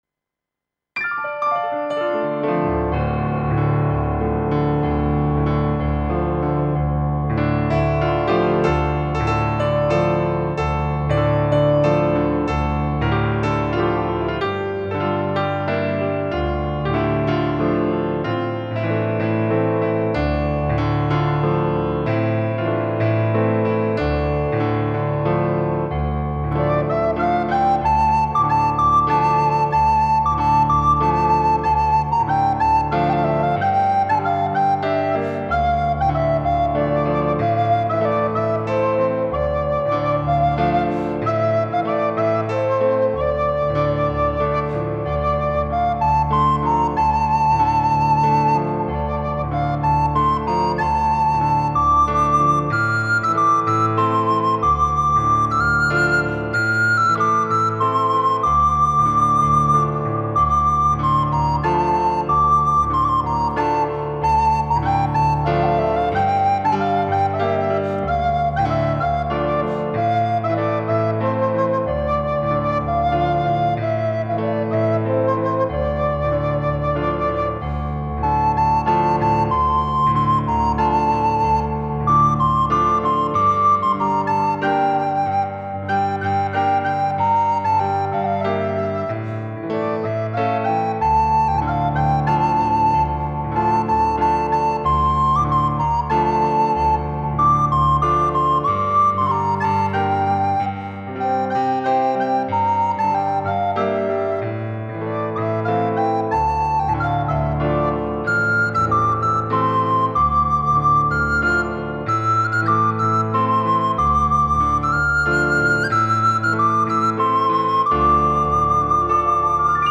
ایرانی